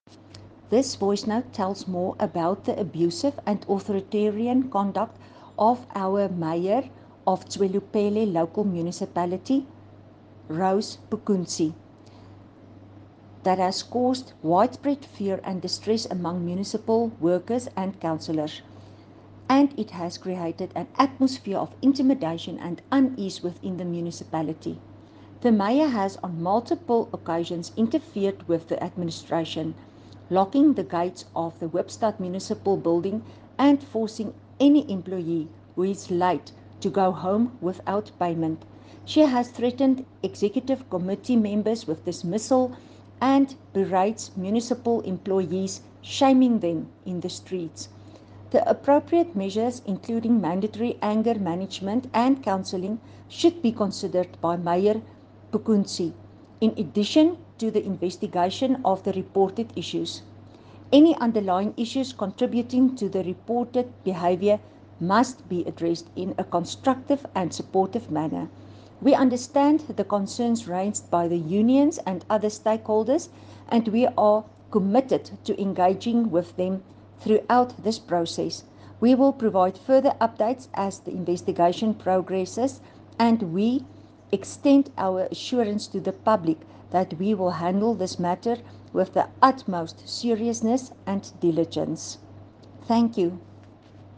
Afrikaans soundbites by Cllr Estelle Pretorius.